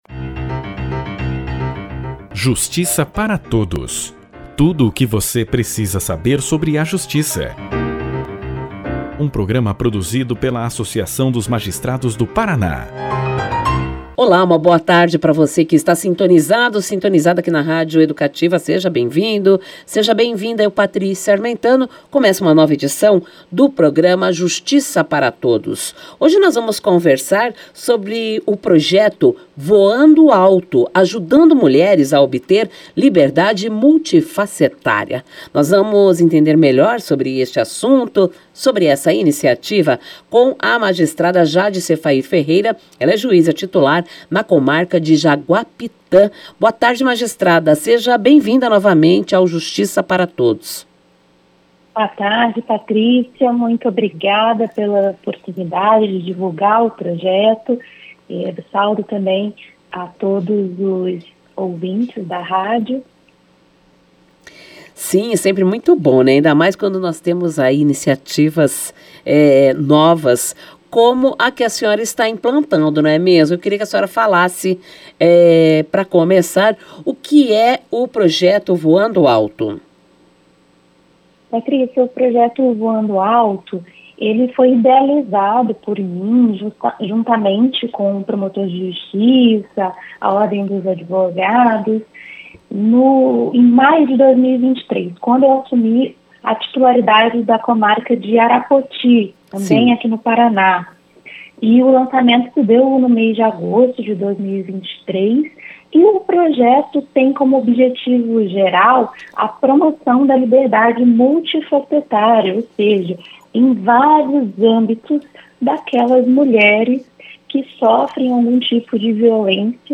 Juíza titular da Comarca de Jaguapitã, a magistrada Jade Seffair Ferreira falou ao programa Justiça para Todos sobre a atuação multidisciplinar e parcerias estratégicas do programa Voando Alto: Ajudando mulheres a obter liberdade multifacetária.